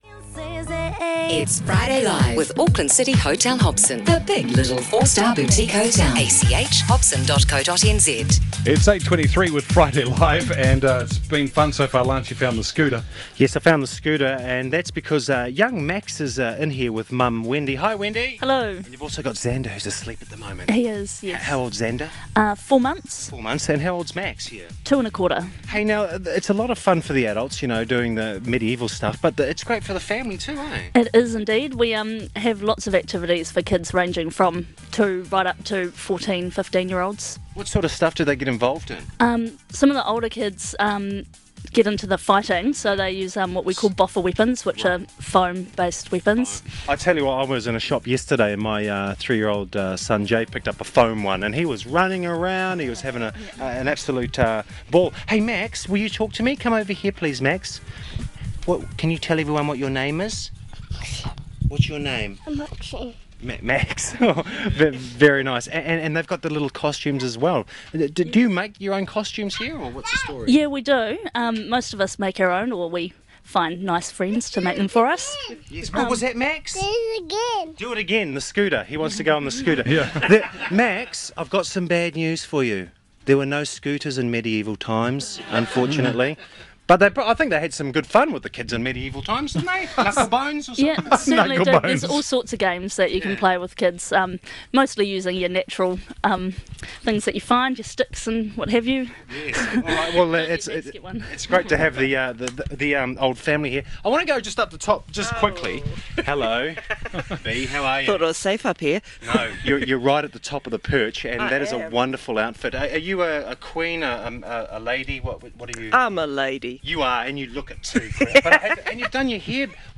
More correctly, the hosts of BigFM invited us to come along and be part of their Friday breakfast show and to talk about who we are and what we do.